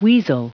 Prononciation du mot weasel en anglais (fichier audio)
weasel.wav